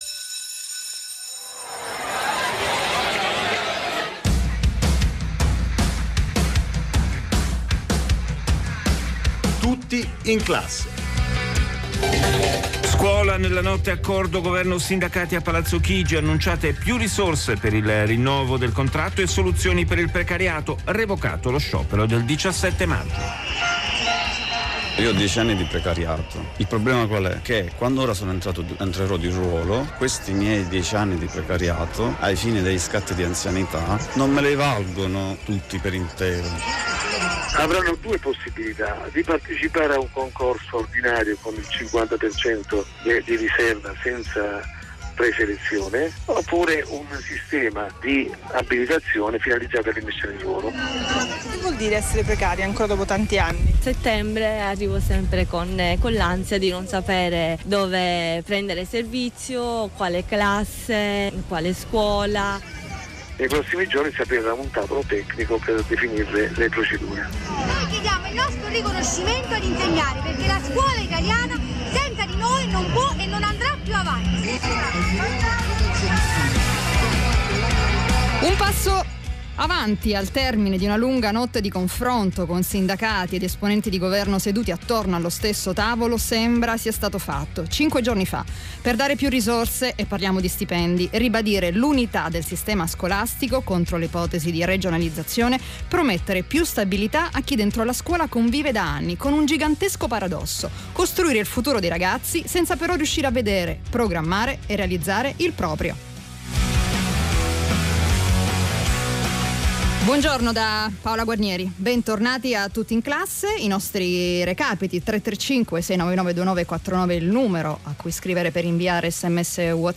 Trasmissione di RAI Radio 1 "TUTTI IN CLASSE"